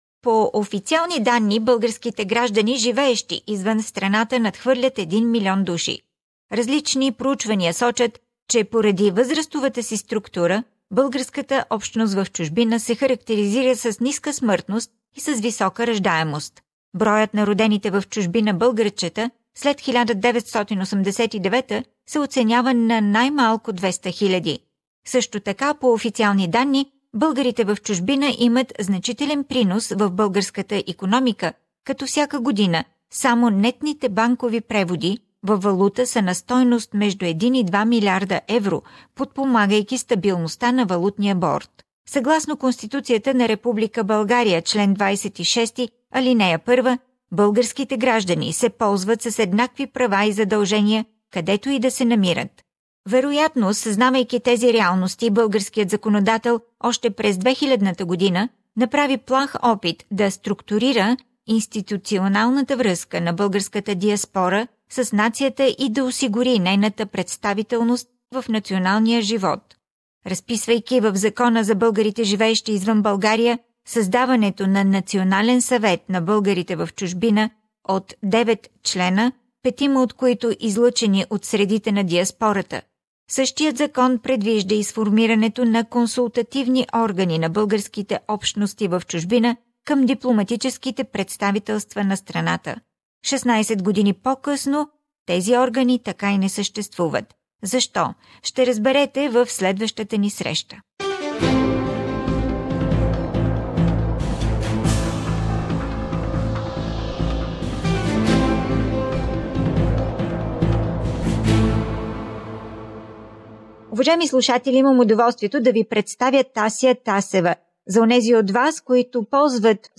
In an exclusive interview for the Bulgarian program on SBS Radio